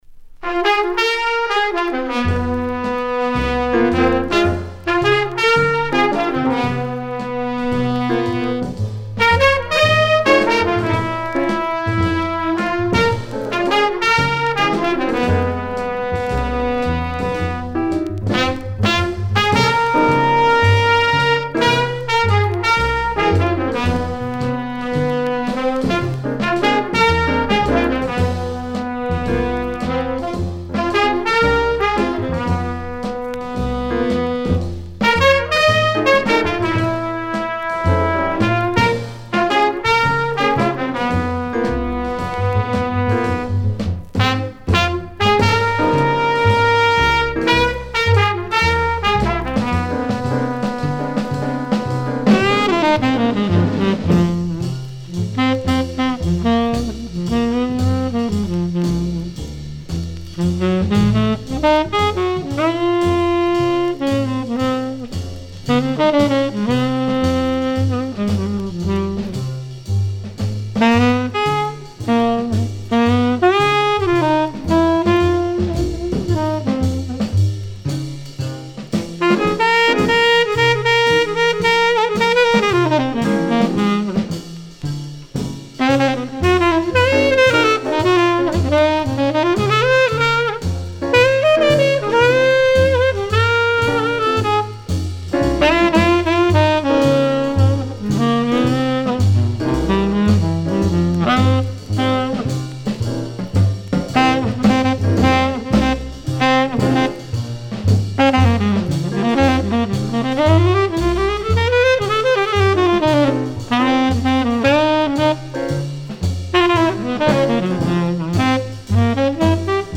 Original mono pressing